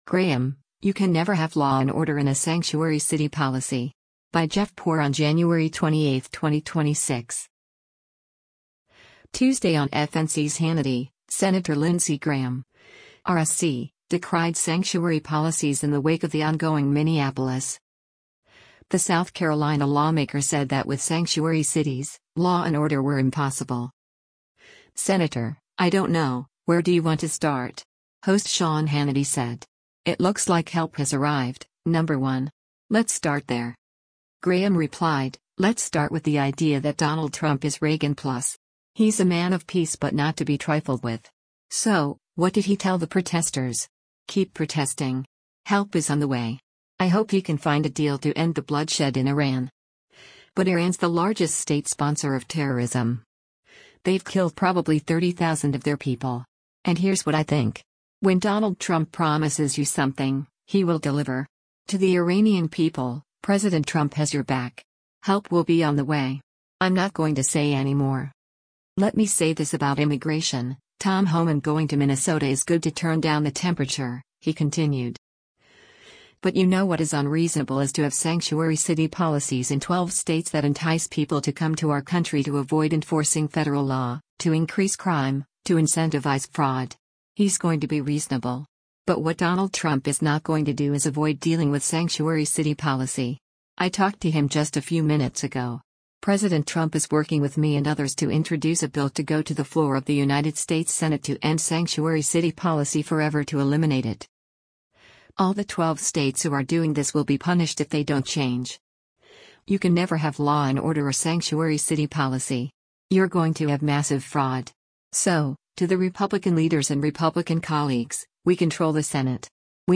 Tuesday on FNC’s “Hannity,” Sen. Lindsey Graham (R-SC) decried sanctuary policies in the wake of the ongoing Minneapolis.